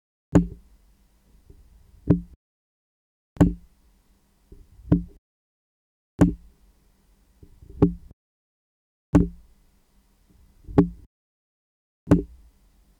ボタンを押すことで、操作の音が入ってしまうという残念ポイント。
※マイクゲインはmax100の状態です。